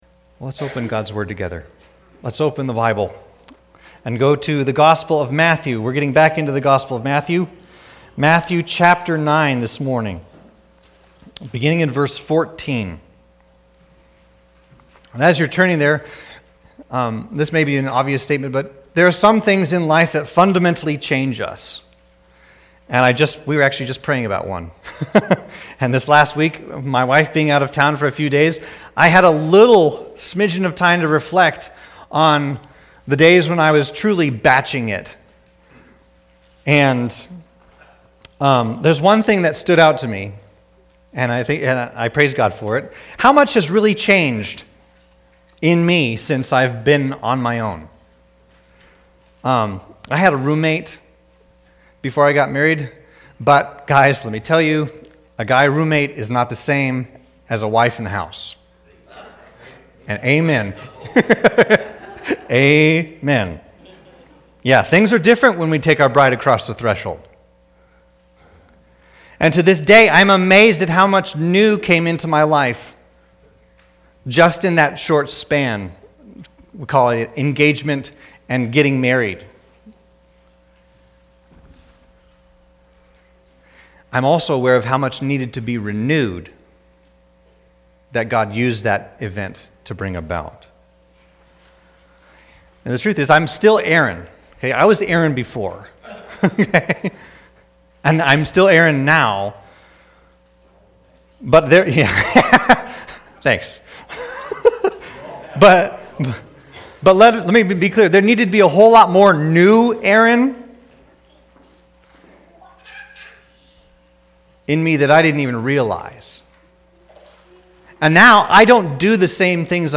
Sermons | York Evangelical Free Church